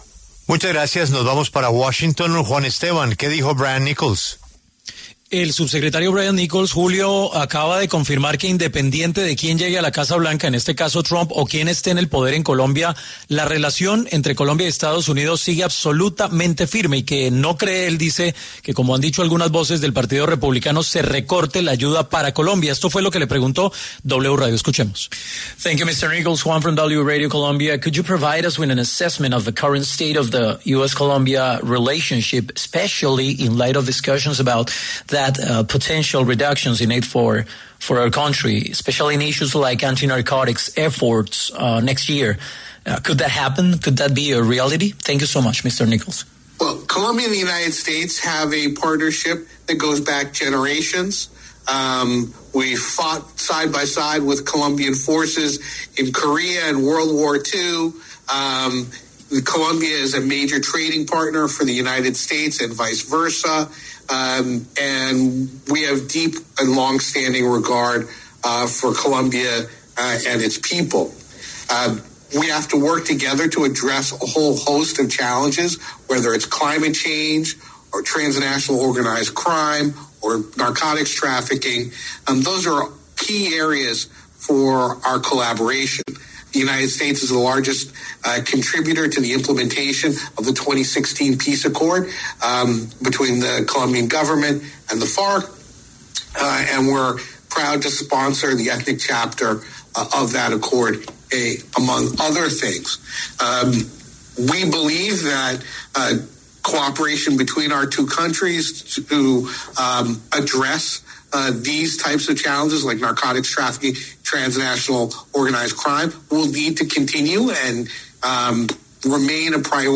Las declaraciones las dio en Nueva York el subsecretario para asuntos del hemisferio occidental Brian Nichols.
Este lunes, desde Nueva York, el subsecretario para asuntos del hemisferio occidental, Brian Nichols, respondió a una pregunta de W Radio sobre el estado de la relación bilateral teniendo en cuenta los cambios que se avecinan con la nueva administración Trump y la posibilidad de un recorte en la ayuda para Colombia en el Congreso de Estados Unidos.